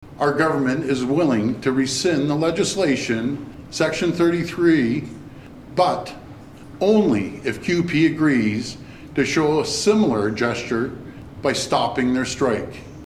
Doug Ford held a news conference Monday morning at Queen’s Park.